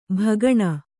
♪ bhagaṇa